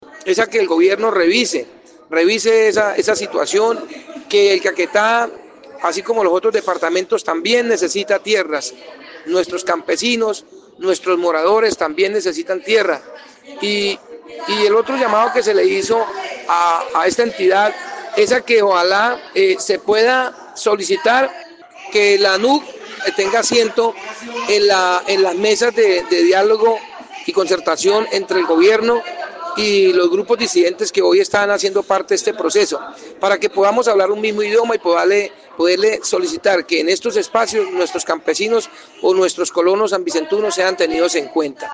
Así lo dio a conocer el diputado por Alianza Verde, Wilman
DIPUTADO_WILMAN_FIERRO_LUGO_TIERRAS_-_copia.mp3